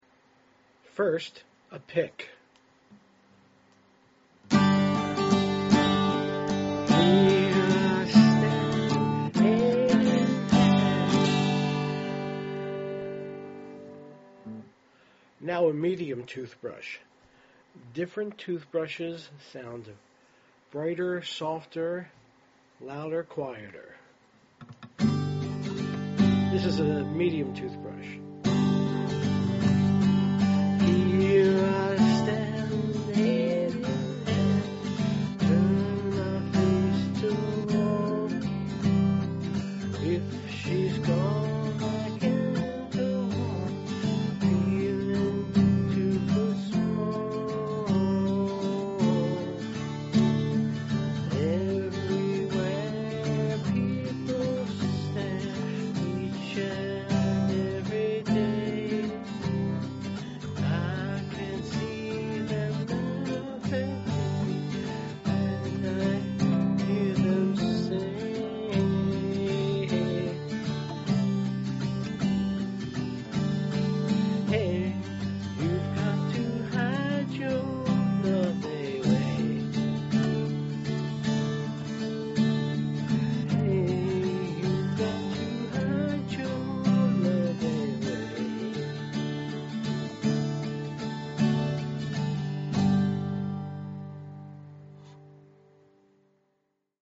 Toothbrush strumming is free, easy and makes every acoustic guitar sound silky smooth.
1. You get a jangly, chorus-like (compressed) shimmer – like it has extra strings!
Audio Demos